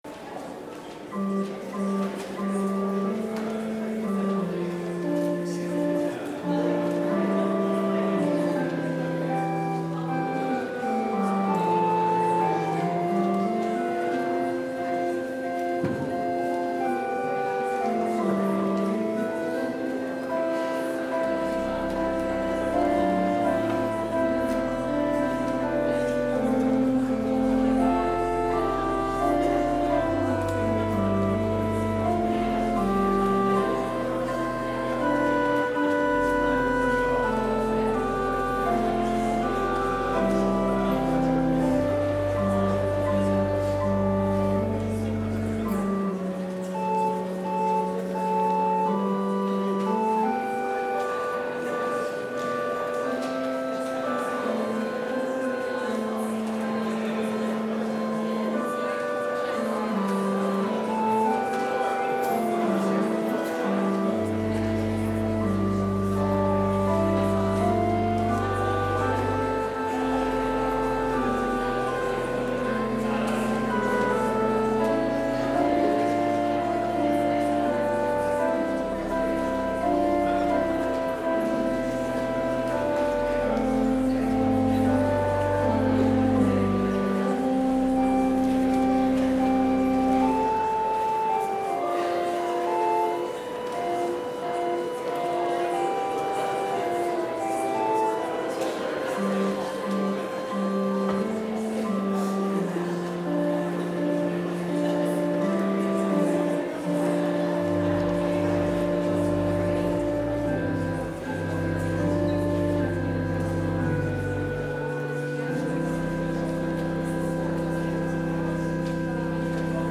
Complete service audio for Chapel - February 9, 2022